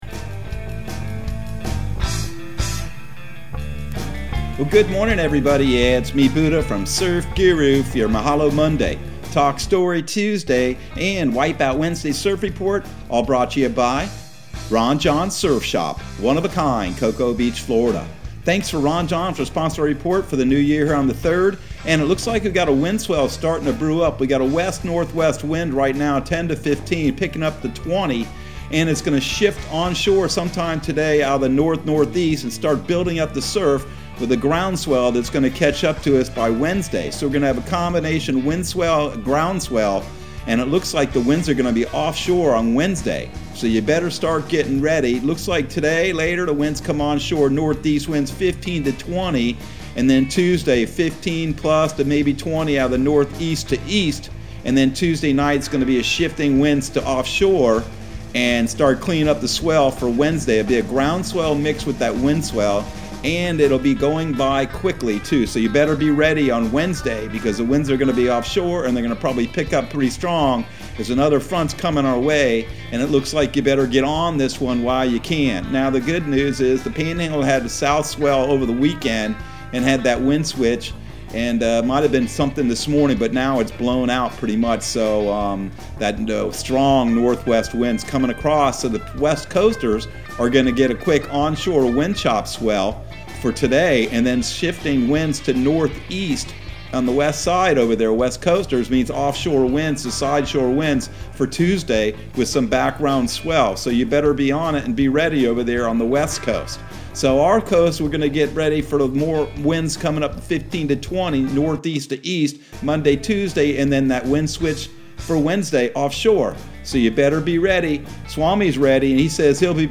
Surf Guru Surf Report and Forecast 01/03/2022 Audio surf report and surf forecast on January 03 for Central Florida and the Southeast.